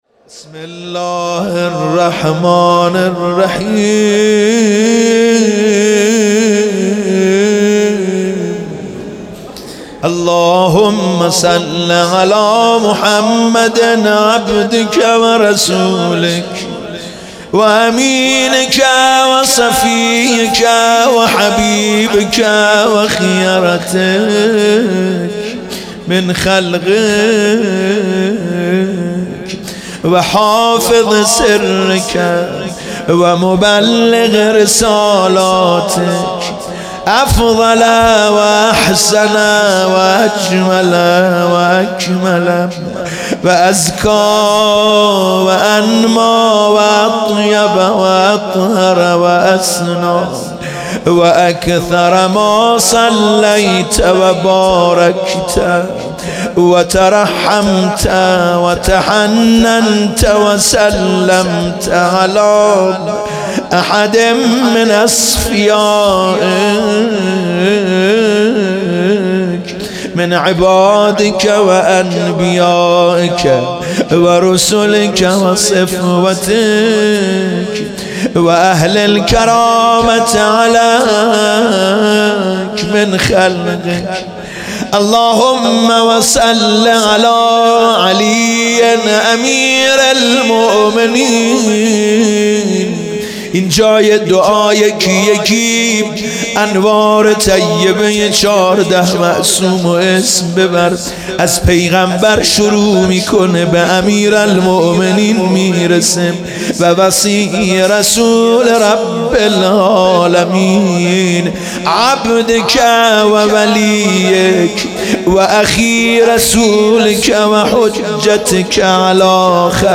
شب دوم ماه رمضان 98 - روضه